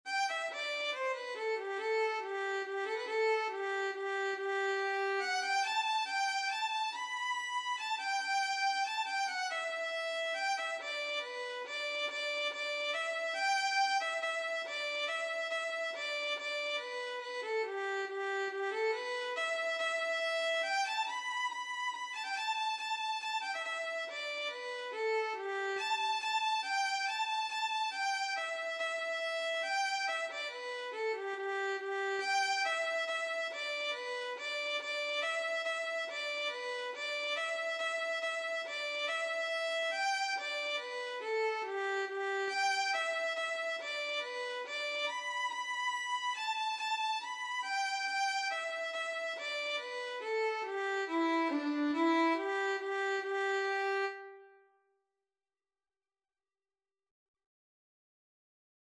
Traditional Turlough O Carolan Elizabeth Nugent Violin version
G major (Sounding Pitch) (View more G major Music for Violin )
3/4 (View more 3/4 Music)
=140 Allegretto
D5-B6
Violin  (View more Easy Violin Music)
Traditional (View more Traditional Violin Music)